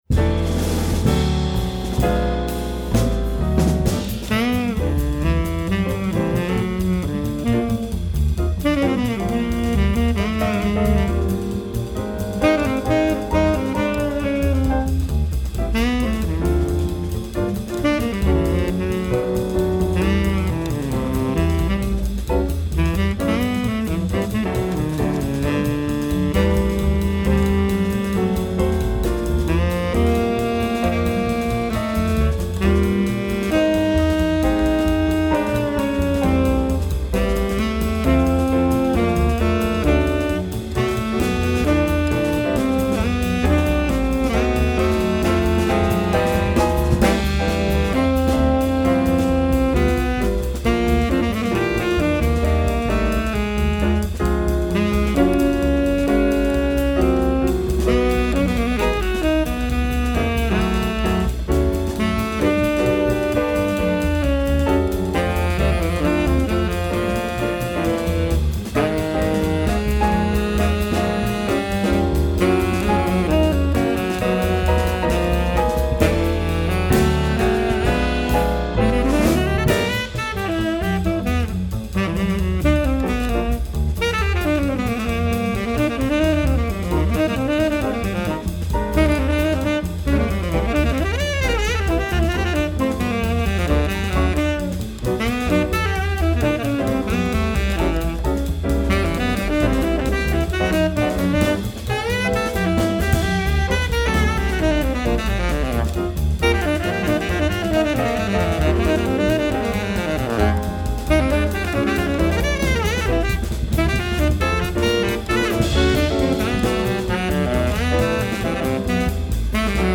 tenor sax
piano
bass
drums. guest
trumpet & flugelhorn